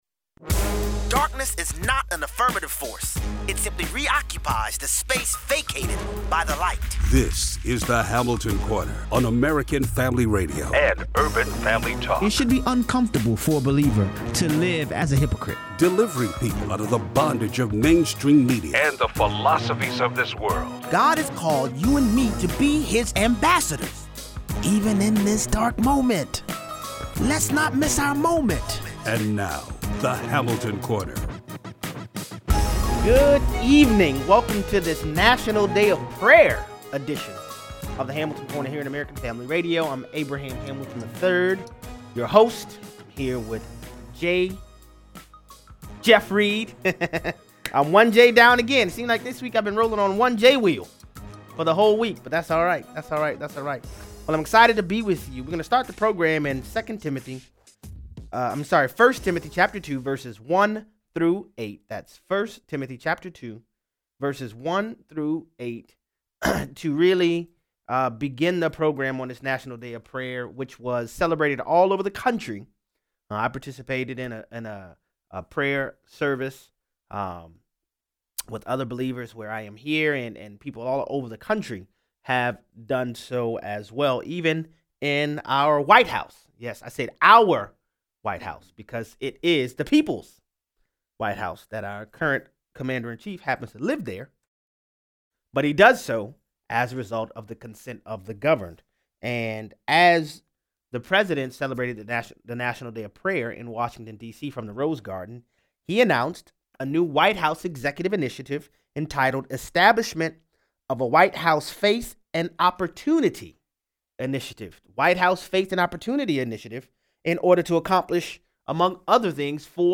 If you think Facebook is done suppressing Christian and conservative content, think again. Callers weigh in.